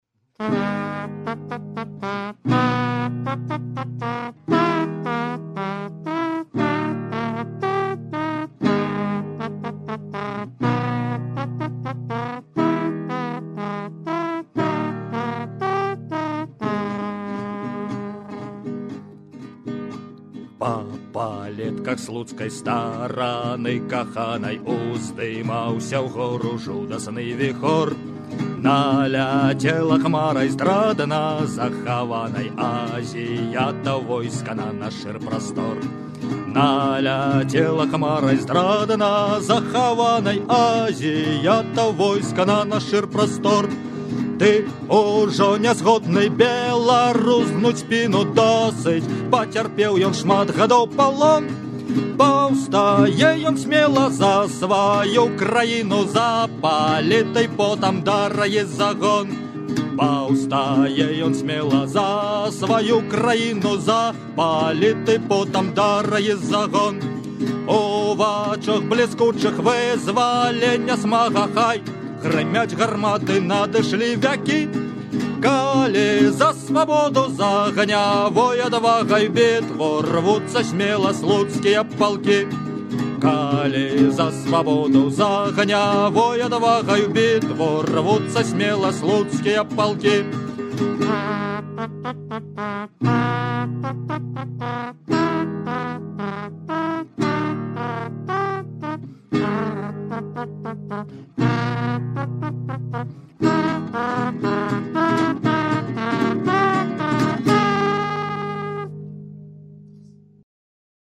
архіўны запіс